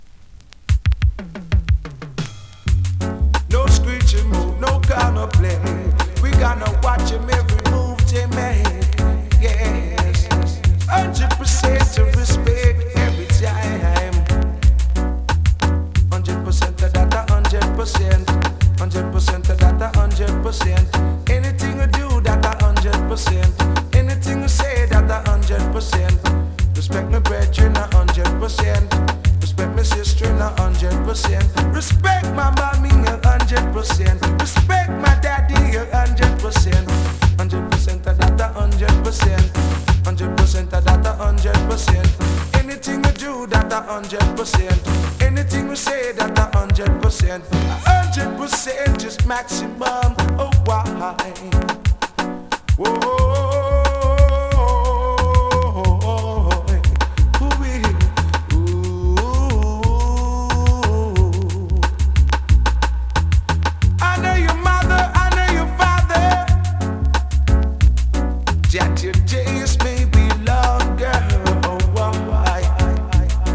ホーム > 2026 NEW IN!! DANCEHALL!!
スリキズ、ノイズ比較的少なめで